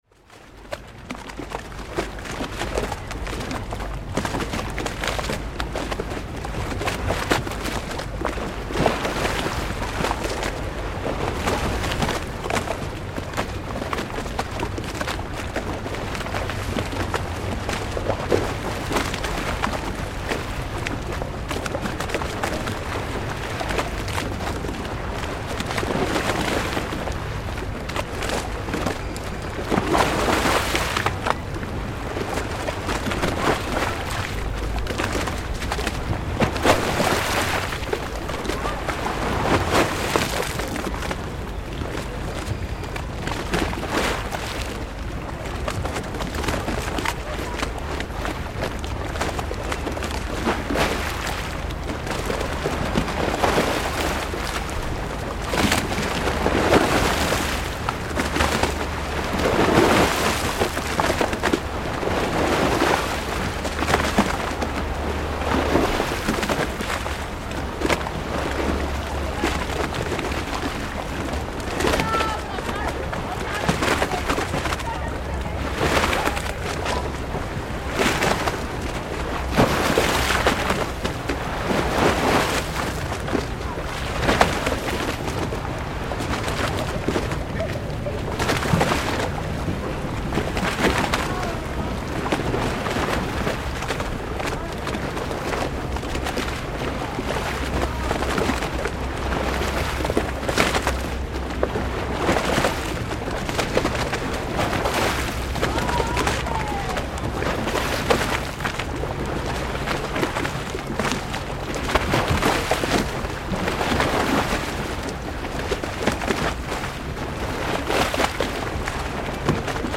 The sound of Venetian gondolas
One of the classic sounds of Venice, a row of moored gondolas sloshing back and forth, sometimes clattering into one another, on the lagoon close to the tourist epicentre of the city, Piazza San Marco.
Passing boat traffic intensifies the breaking water, which splashes up onto the pavement, endangering our brave little recording device.